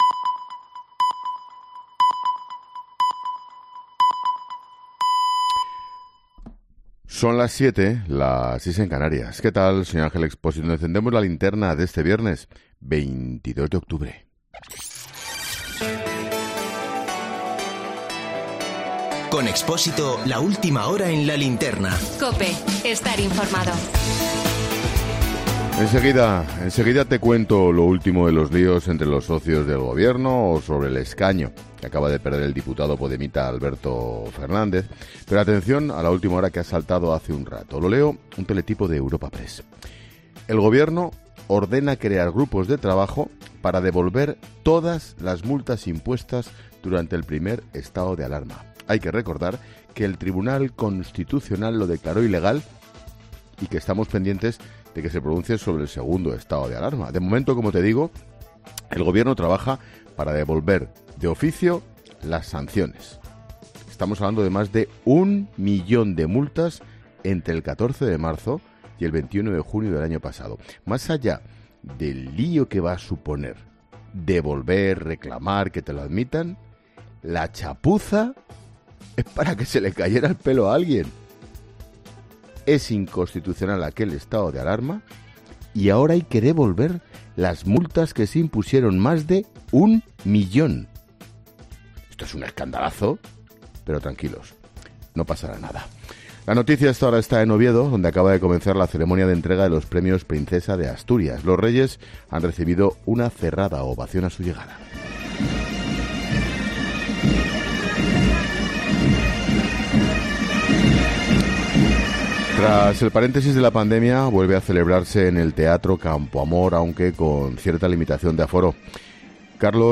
Monólogo de Expósito. Noticias del día.